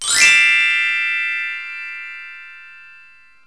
/ cdmania.iso / sounds / bells / hiscale.wav ( .mp3 ) < prev next > Waveform Audio File Format | 1996-04-15 | 39KB | 1 channel | 22,050 sample rate | 3 seconds
hiscale.wav